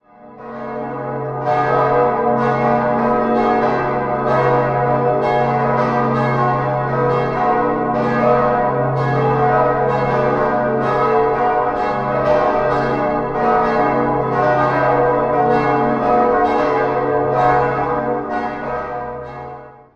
6-stimmiges erweitertes Salve-Regina-Geläute: h°-dis'-fis'-gis'-h'-dis''
bell
Ein historisch bedeutsames Geläute des 19. Jahrhunderts komplett aus einer Gießerhand.